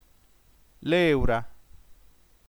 lèura nf lé.u.ra - ['leuɾa] ◊